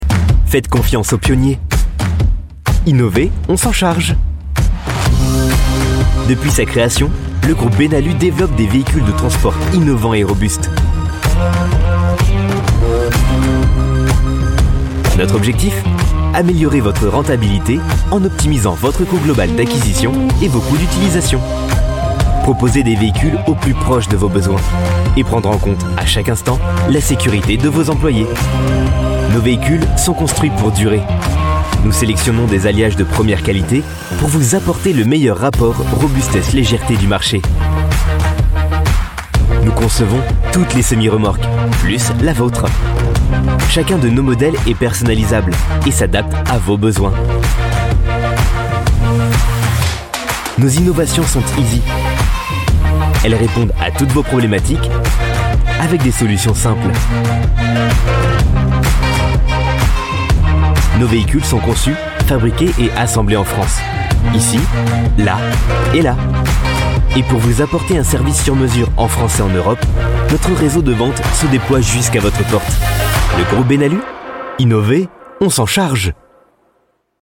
Tanıtım Demo